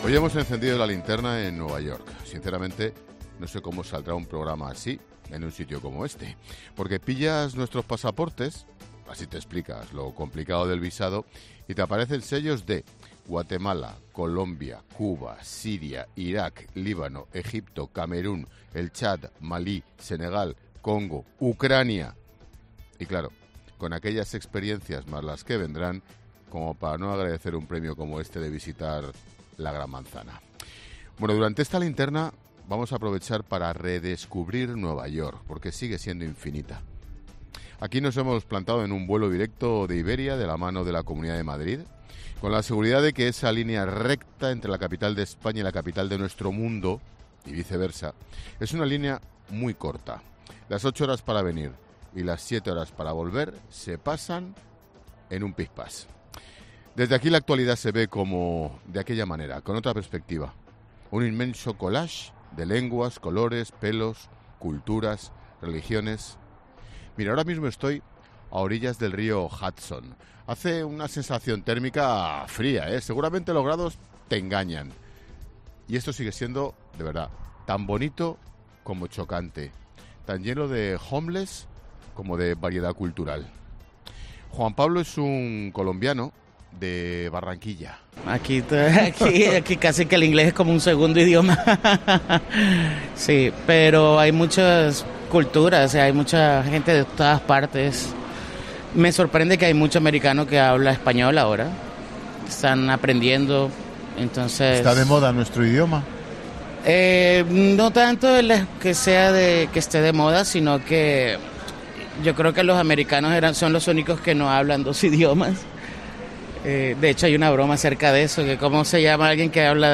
Ángel Expósito, desde Times Square: Me cruzo con mucha gente hablando español, la Hispanidad es global